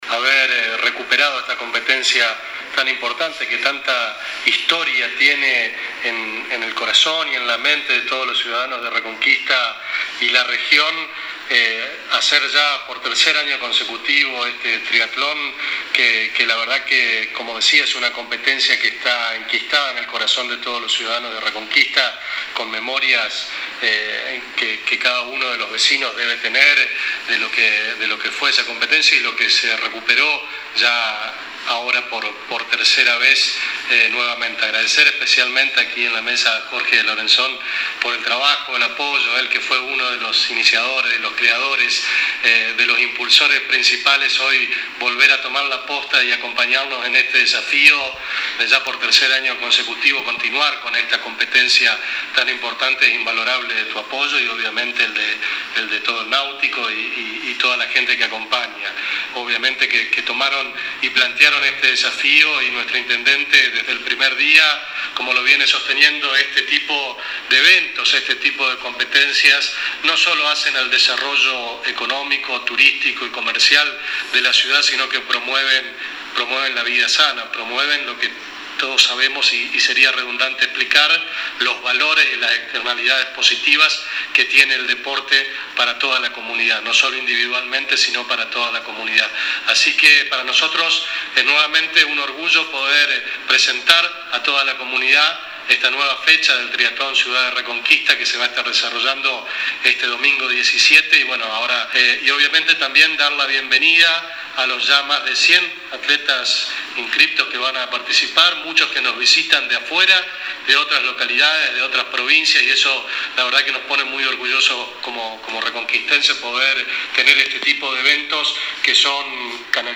se realizó en la mañana de este jueves 14 de febrero en conferencia de prensa el lanzamiento oficial del Triatlón “Ciudad de Reconquista”.